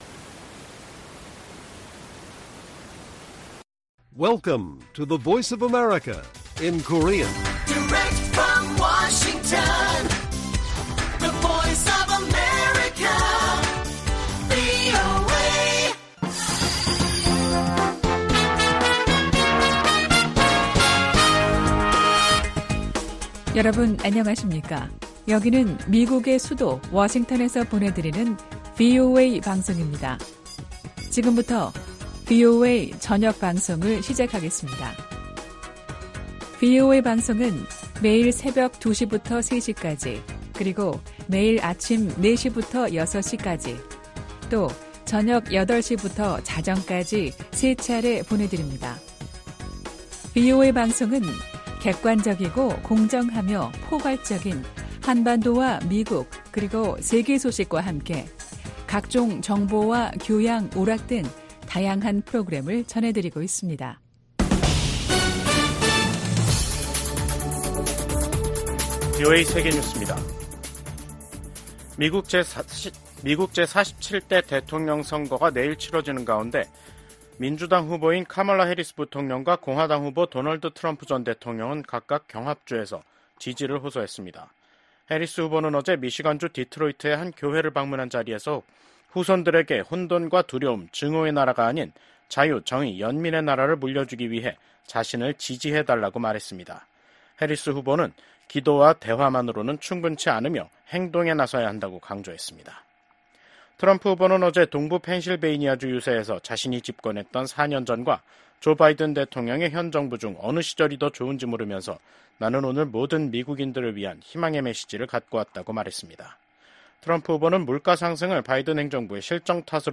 VOA 한국어 간판 뉴스 프로그램 '뉴스 투데이', 2024년 11월 4일 1부 방송입니다. 북한의 러시아 파병 문제가 국제적인 중대 현안으로 떠오른 가운데 북러 외교수장들은 러시아의 우크라이나 전쟁을 고리로 한 결속을 강조했습니다. 미국과 한국의 외교, 국방 수장들이 북러 군사협력 심화와 북한의 대륙간탄도미사일 발사를 강력히 규탄했습니다. 10개월 만에 재개된 북한의 대륙간탄도미사일 발사를 규탄하는 국제사회의 목소리가 이어지고 있습니다.